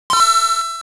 Sound effect of "Coin+" in Wario Land: Super Mario Land 3.
WL_Coin+.oga